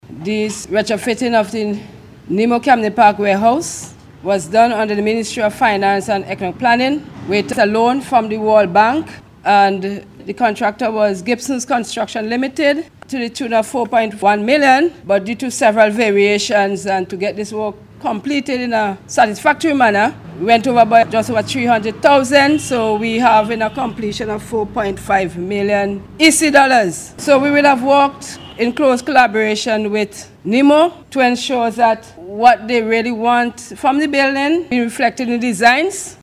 during a brief ceremony which was held on Wednesday for the handing over of the facility.